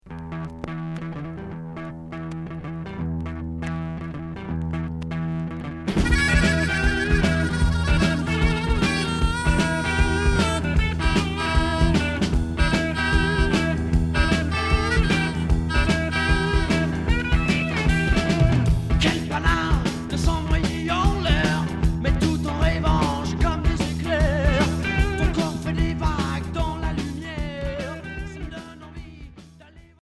Rock Unique 45t